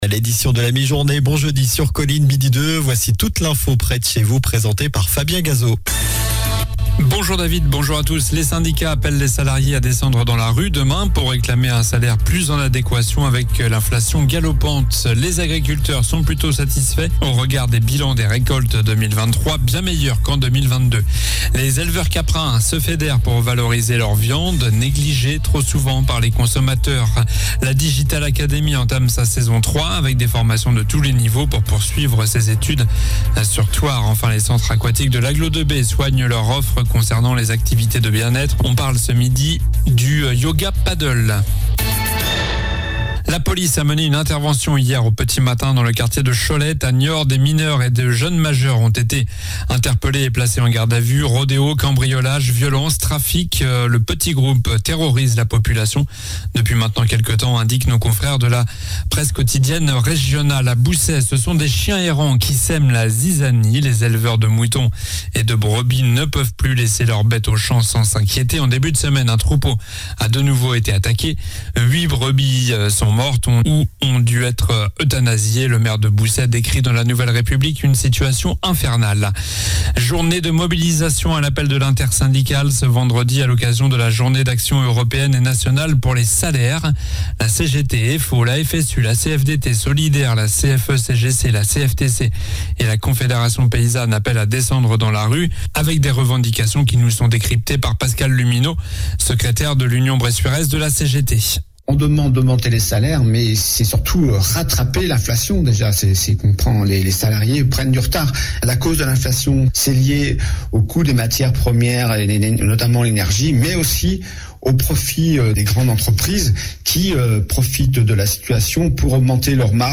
Journal du jeudi 12 octobre (soir)